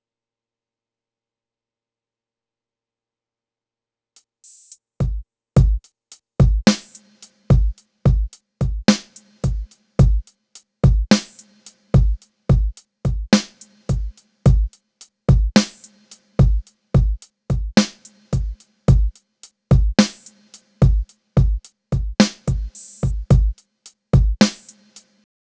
DRUMS16.wav